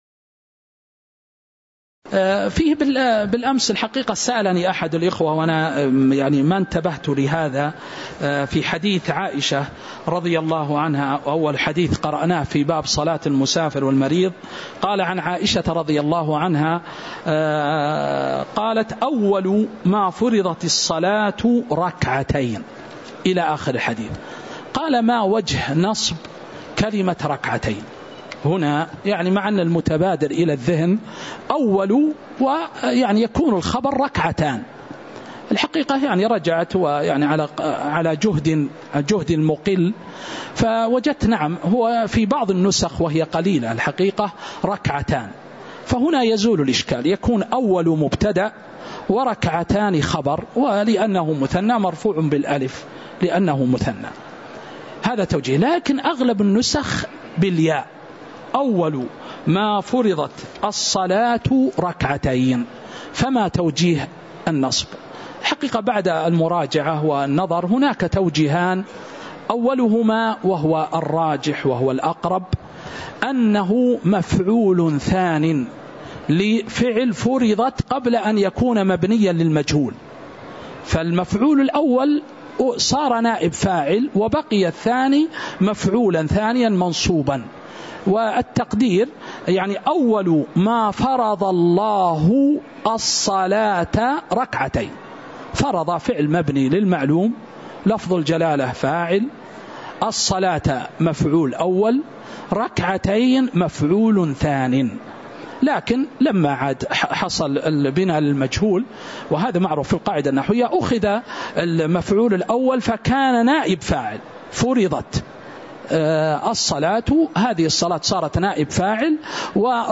تاريخ النشر ٢ رجب ١٤٤٥ هـ المكان: المسجد النبوي الشيخ